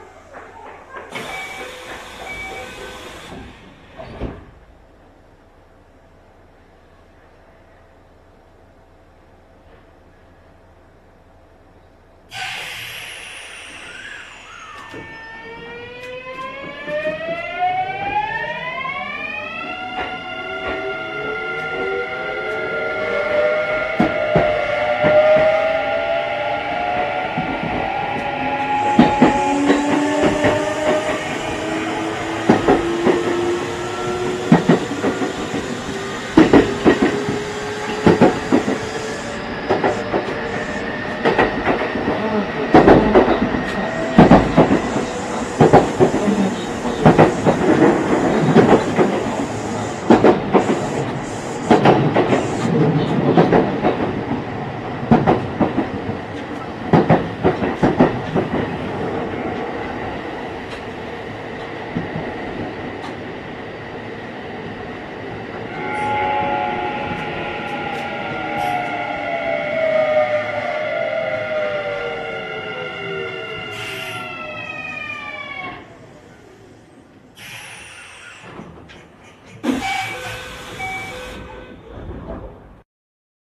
日立は3220系と同じですが、三菱は独特で非同期モードがまるでGTOのような音がします。
走行音
収録区間：奈良線 枚岡→額田